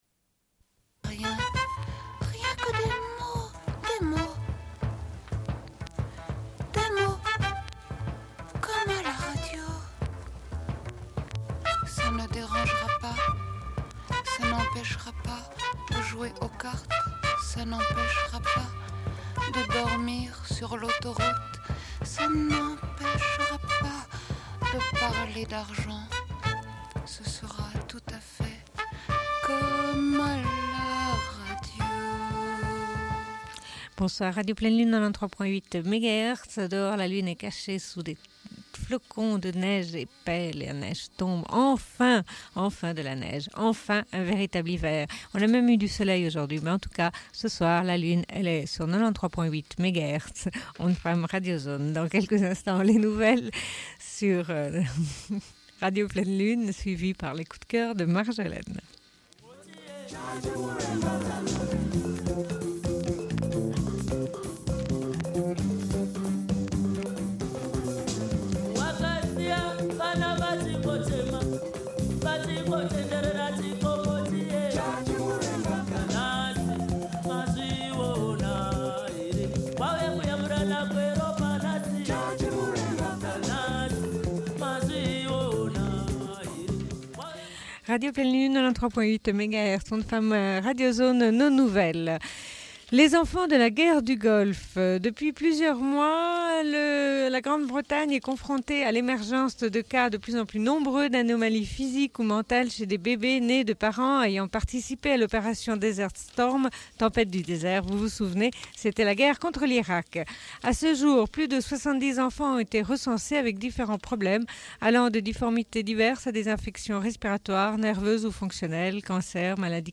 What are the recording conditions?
Une cassette audio, face B